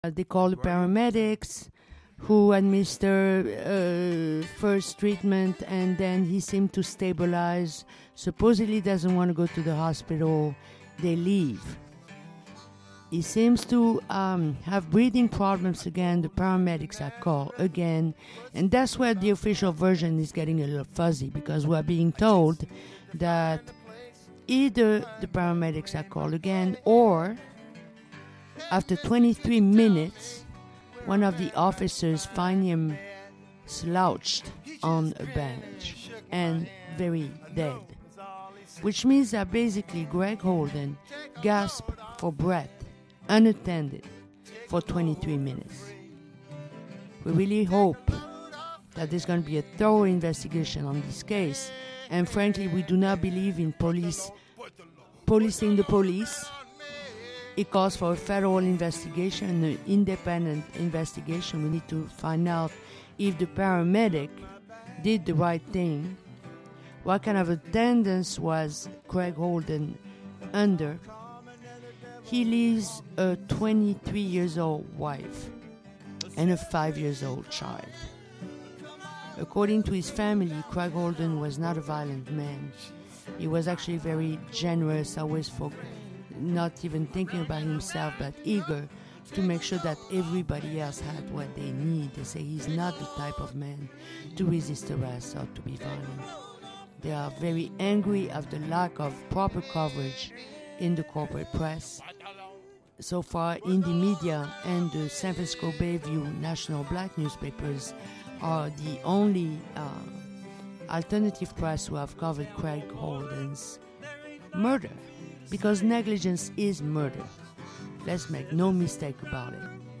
Law Enforcement Accountability Talk show / October 5-05 noon to 1 PM